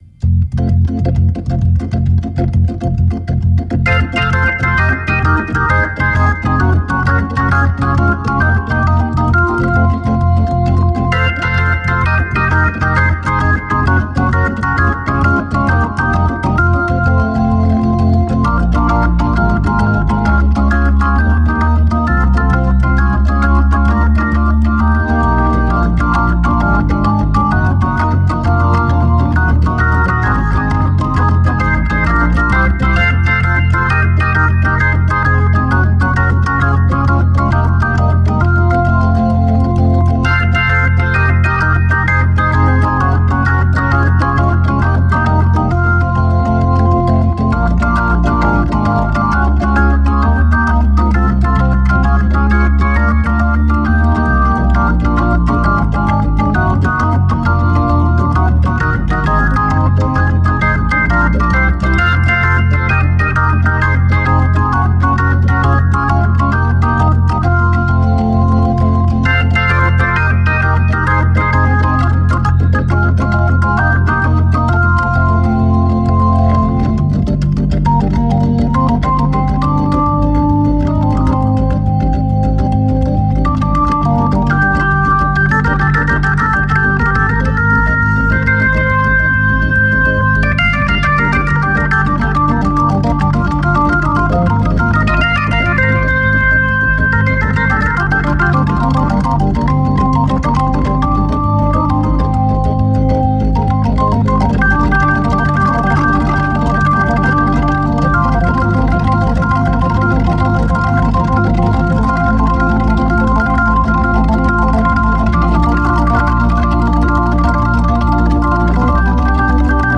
One gets quite a solemn feeling sitting there in the middle.
Hammond organ, 4 leslies
Genres: Experimental